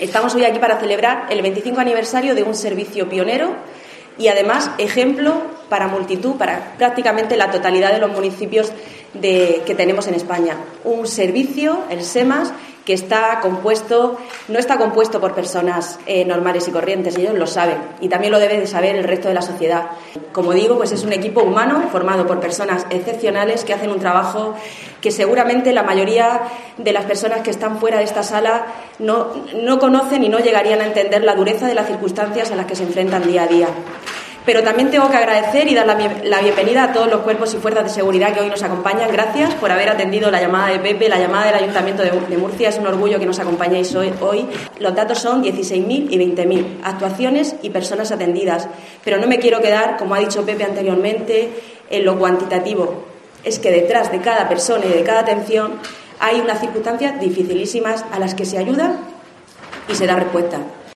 Paqui Pérez, concejala de Servicios Sociales del Ayuntamiento de Murcia